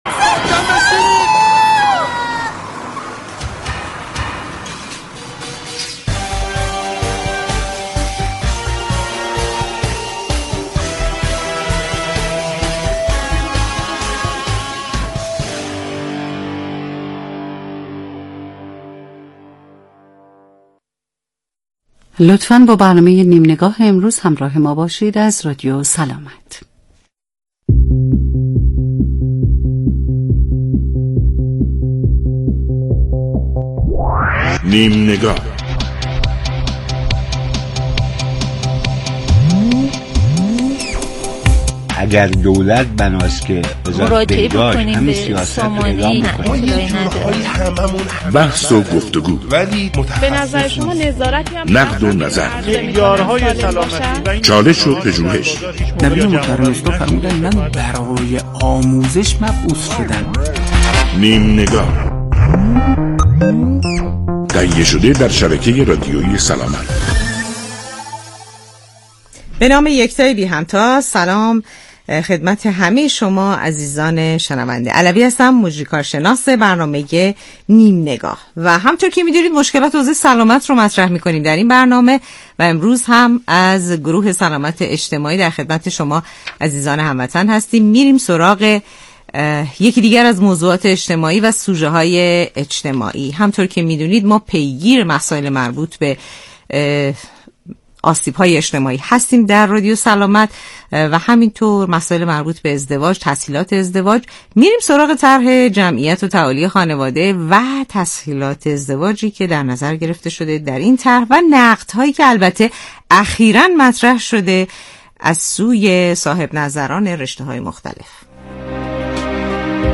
برنامه نیم‌نگاه در شبکه رادیویی سلامت